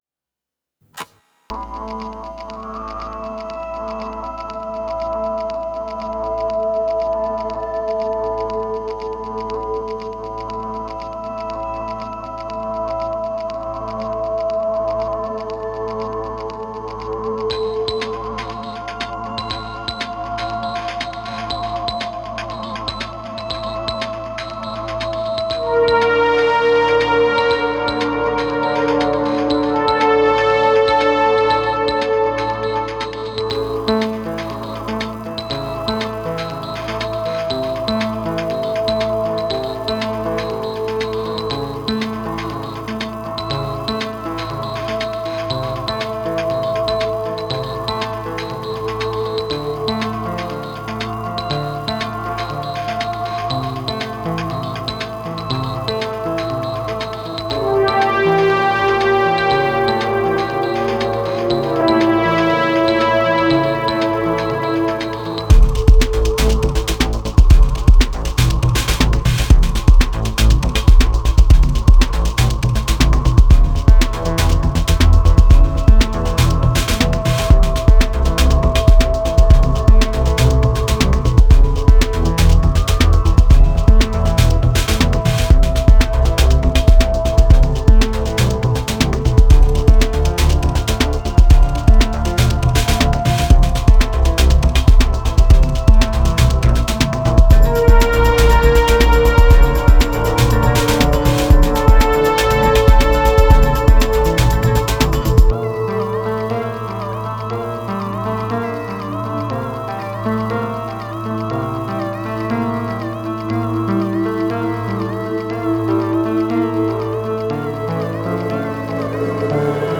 Темп: 120 BPM